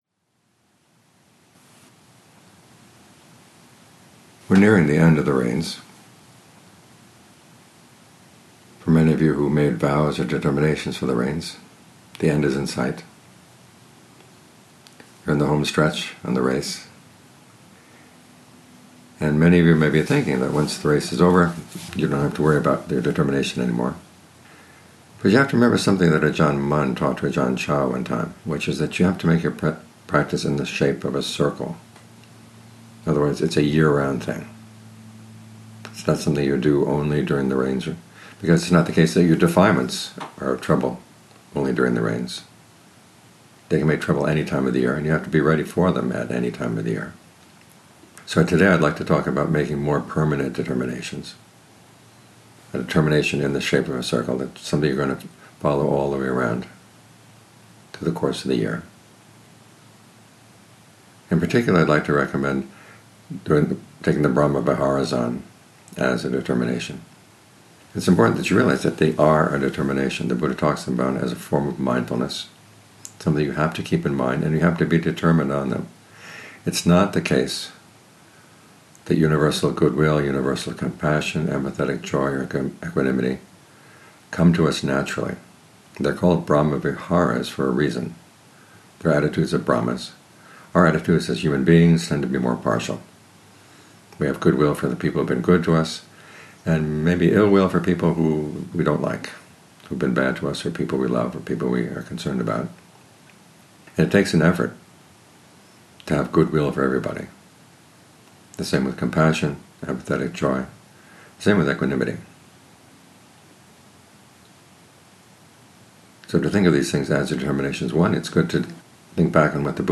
Lectures
These lectures, given by Thanissaro Bhikkhu at various meditation centers and about 40–60 minutes long on average, provide a systematic and focused explanation—and Q&A—on selected Dhamma themes.